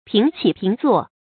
píng qǐ píng zuò
平起平坐发音